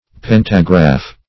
pentagraph - definition of pentagraph - synonyms, pronunciation, spelling from Free Dictionary